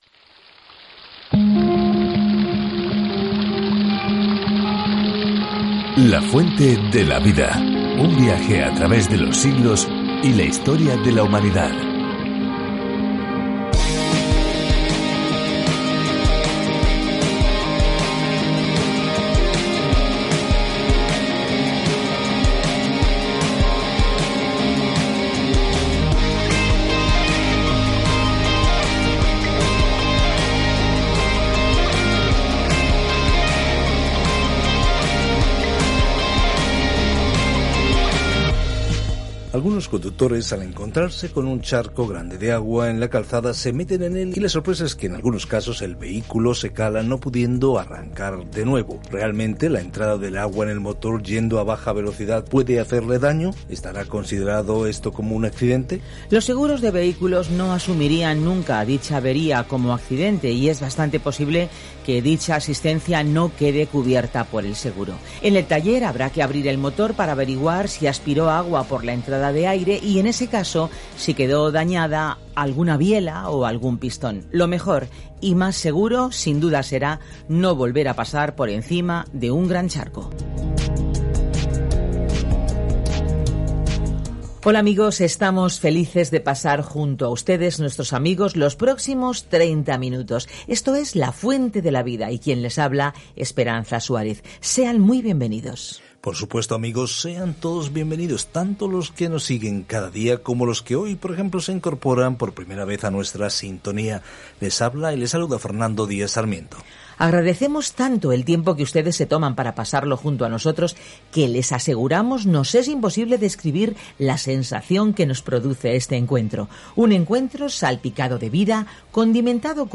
Escritura MALAQUÍAS 1:14 MALAQUÍAS 2:1-2 Día 3 Iniciar plan Día 5 Acerca de este Plan Malaquías le recuerda a un Israel desconectado que tiene un mensaje de Dios antes de que soporten un largo silencio, que terminará cuando Jesucristo entre en escena. Viaje diariamente a través de Malaquías mientras escucha el estudio de audio y lee versículos seleccionados de la palabra de Dios.